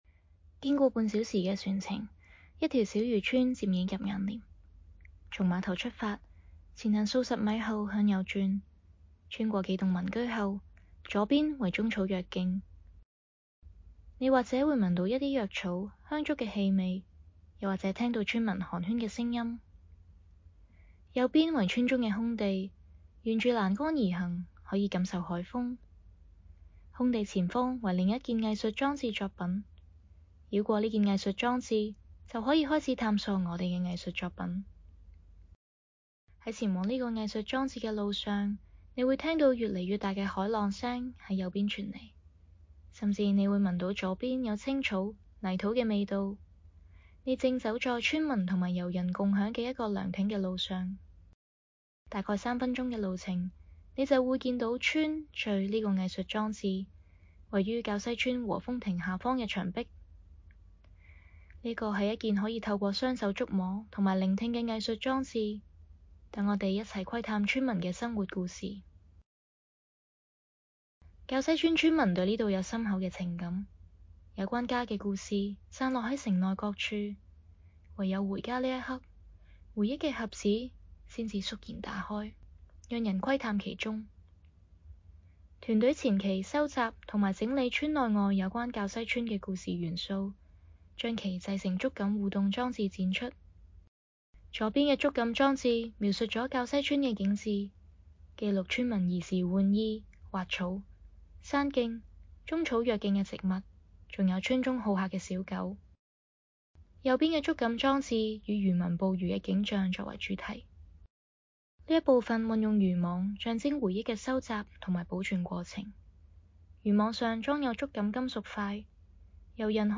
《村。敘》— 藝術家原聲介紹按此閱讀原聲介紹文字稿